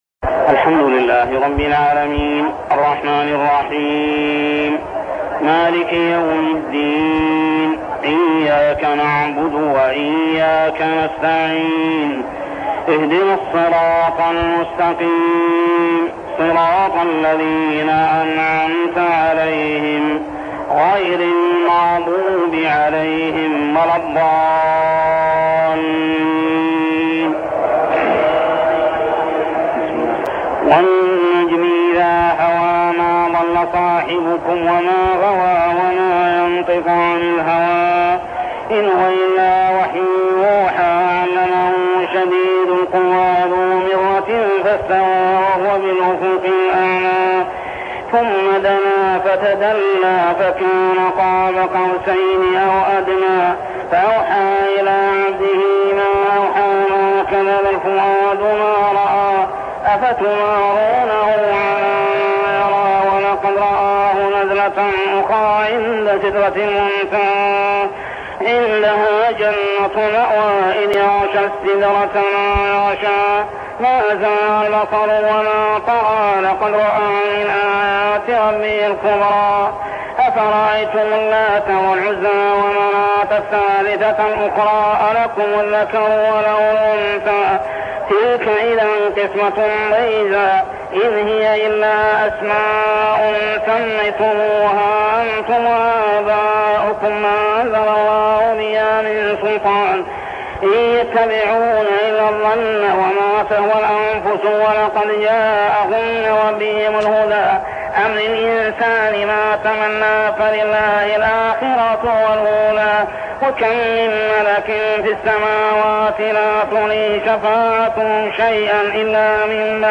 صلاة التراويح عام 1403هـ من سورة النجم كاملة حتى سورة الحديد 1-7 | Tarawih prayer From Surah An-Najm to Surah Al-Hadid > تراويح الحرم المكي عام 1403 🕋 > التراويح - تلاوات الحرمين